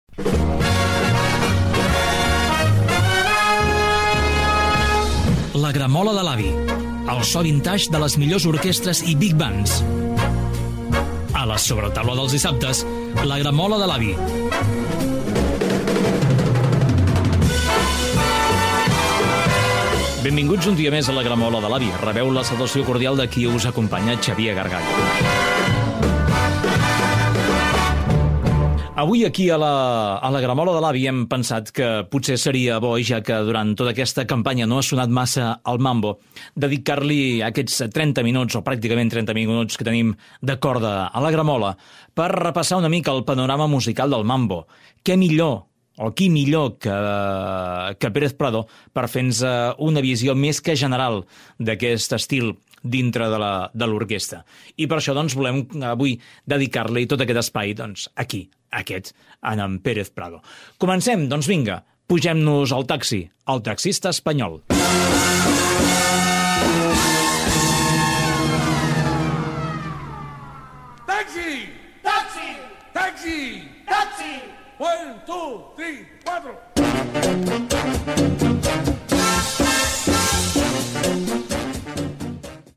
Careta del programa, presentació del programa dedicat al ritme del mambo i tema musical
Musical